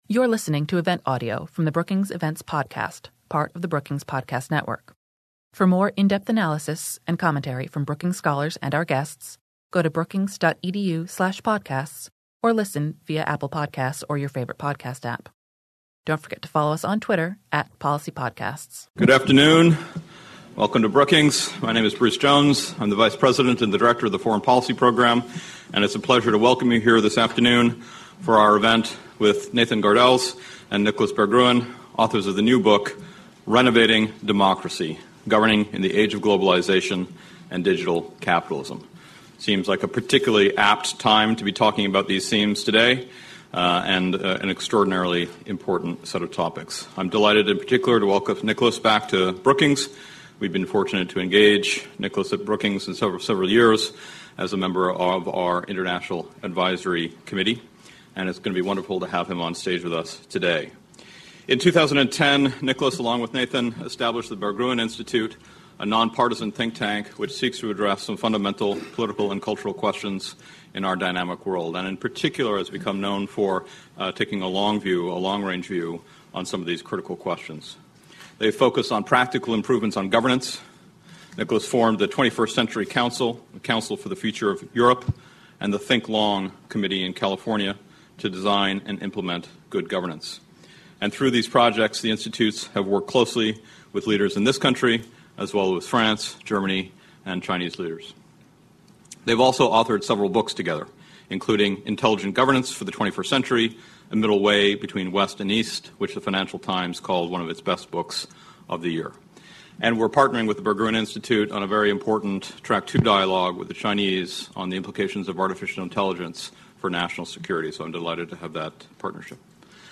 Questions from the audience followed the discussion.